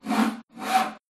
construction.mp3